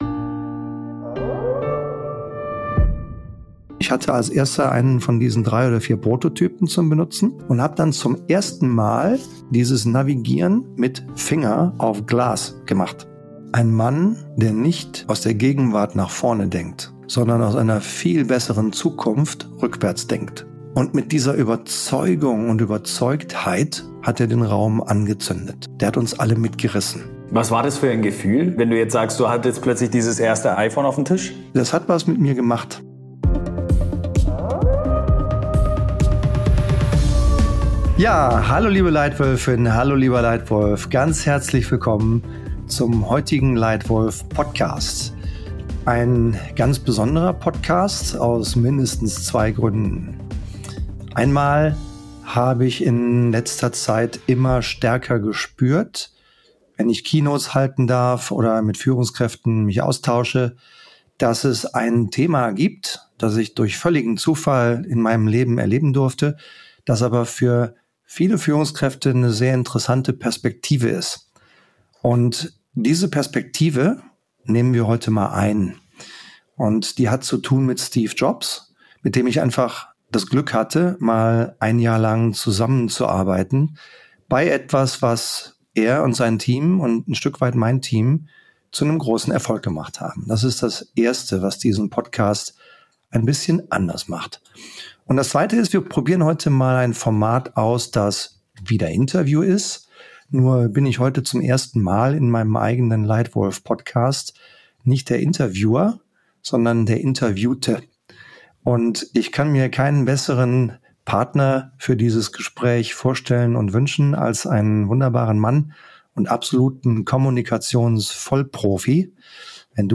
übernimmt für diese Folge das Mikrofon und stellt Fragen